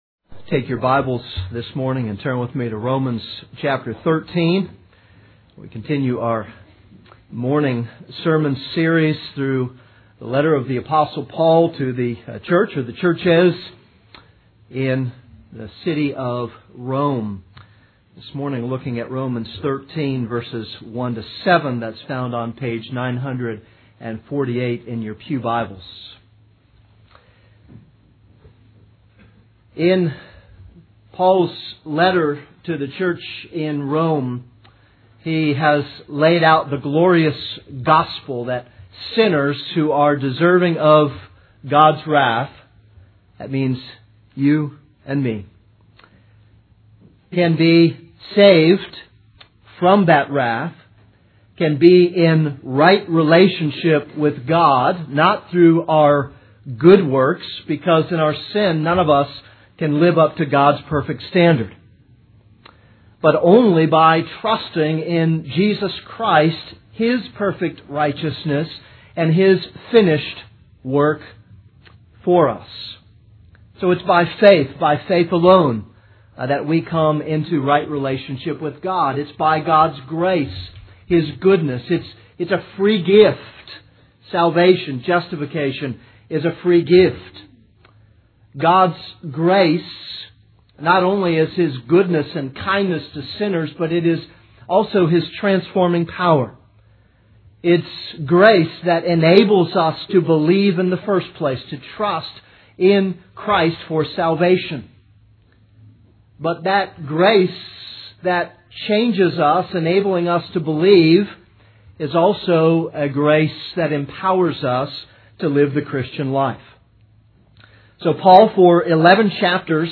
This is a sermon on Romans 13:1-7.